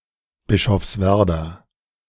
Bischofswerda (German: [bɪʃɔfsˈvɛʁda]
De-Bischofswerda.ogg.mp3